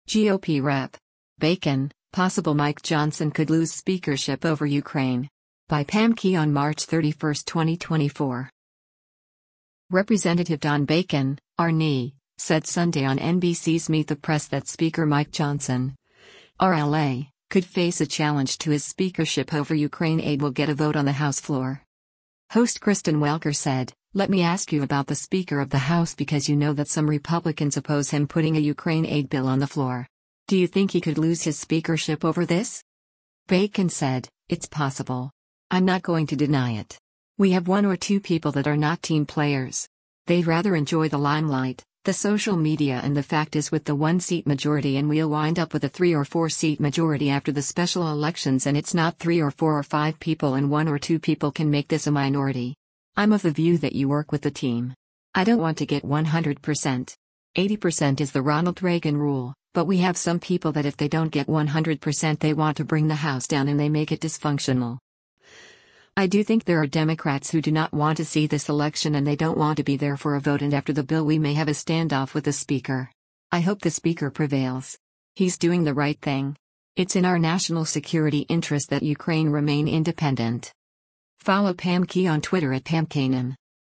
Representative Don Bacon (R-NE) said Sunday on NBC’s “Meet the Press” that Speaker Mike Johnson (R-LA) could face a challenge to his speakership over Ukraine aid will get a vote on the House floor.